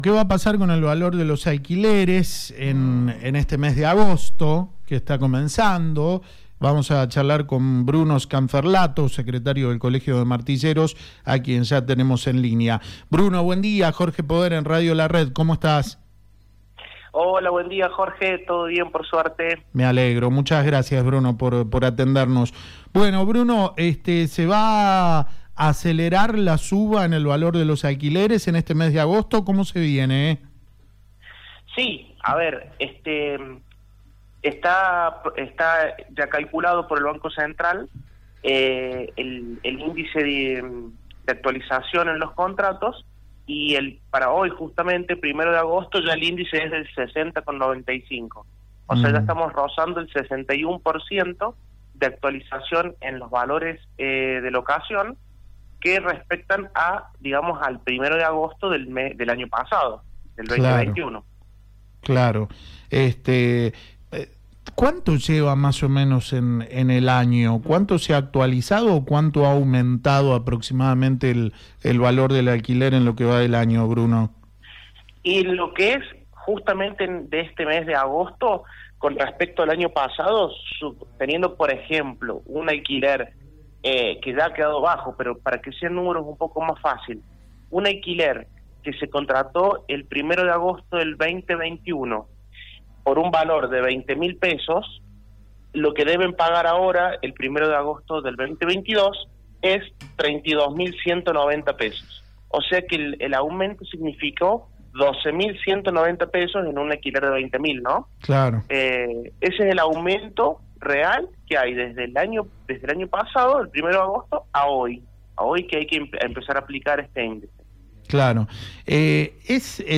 en diálogo con La Red San Luis.